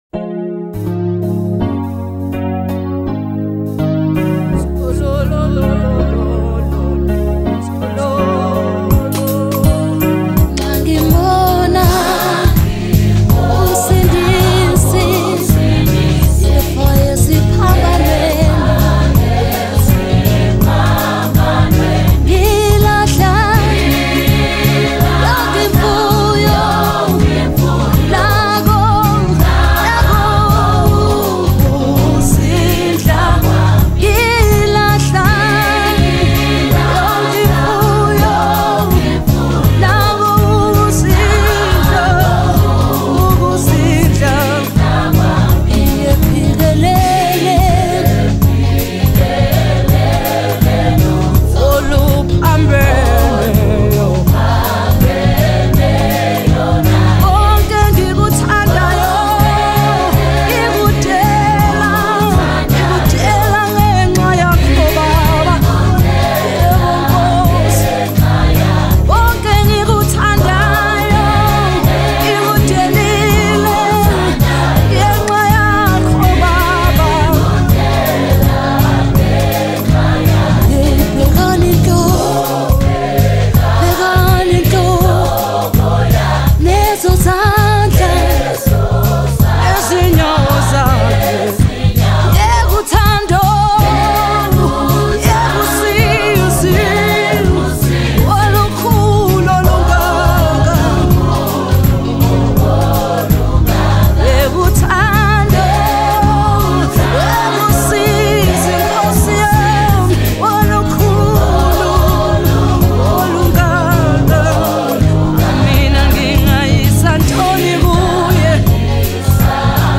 January 30, 2025 Publisher 01 Gospel 0